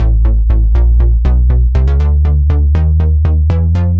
Index of /musicradar/french-house-chillout-samples/120bpm/Instruments
FHC_NippaBass_120-A.wav